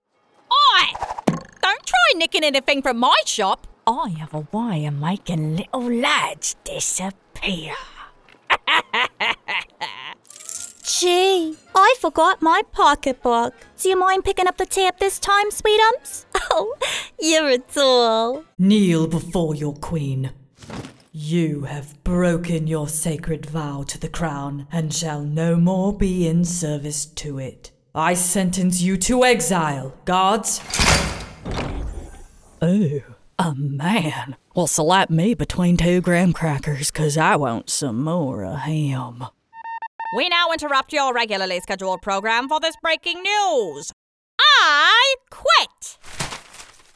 Accent Sizzle